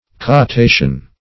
Captation \Cap*ta`tion\, n. [L. captatio, fr. captare to catch,